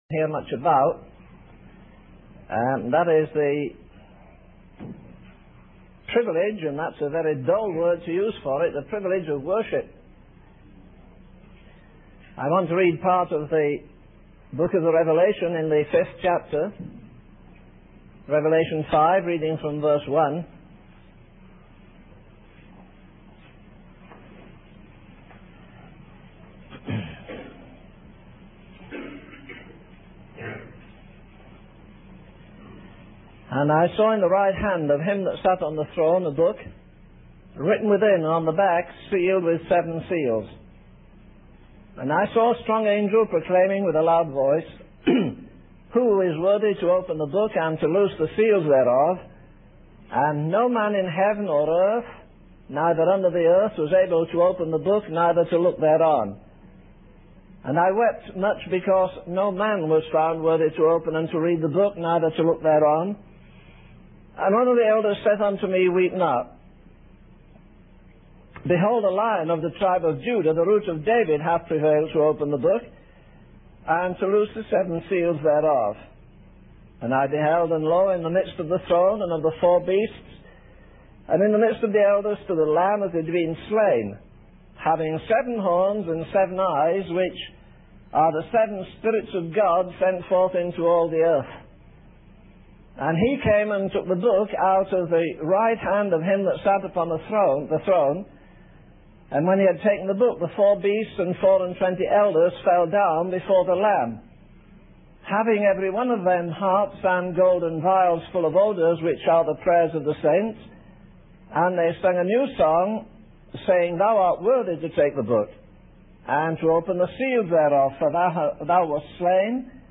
This sermon emphasizes the privilege and importance of worship, focusing on the scene in Revelation 5 where the Lamb is found worthy to open the book and receive worship from all creatures in heaven, on earth, and under the earth. It highlights the need for believers to engage in deep, passionate worship, gazing on the holiness, faithfulness, love, and purity of God, and anticipating the eternal worship in His presence.